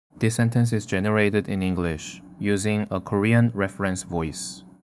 제가 제일 놀란 건 영어 쪽인데, 제 영어 발음 버릇이랑 목소리 톤이 너무 비슷하게 나와서 소름이 돋을 정도였어요.
clone/01_english — 한국어 ref 하나로 영어 크로스링구얼 클로닝
clone_01_english.wav